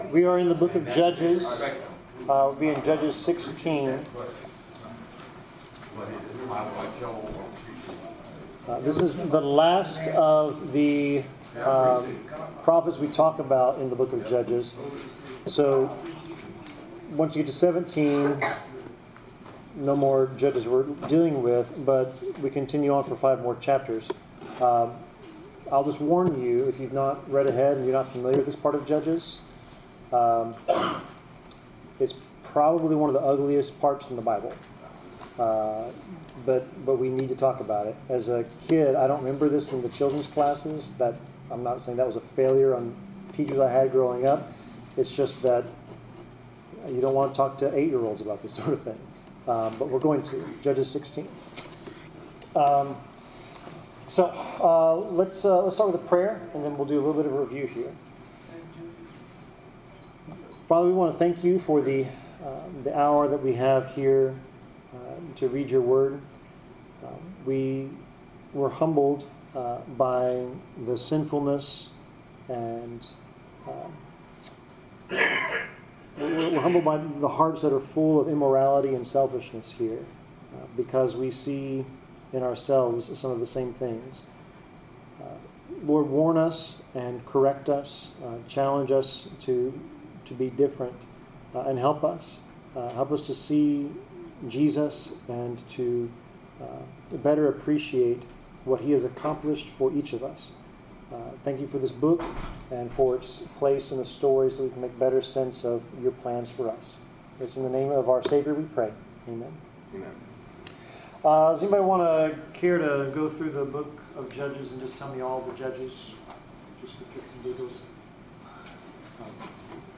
Bible class: Judges 16
Service Type: Bible Class